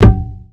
Bongo17.wav